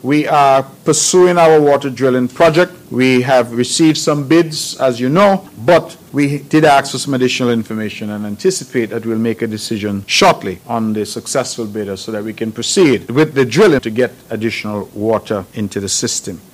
The NIA announced its intentions to commence a Water Drilling Project on Nevis in 2023, with the goal of obtaining three water wells to increase the water supply on the island. Providing the update was Premier Hon. Mark Brantley: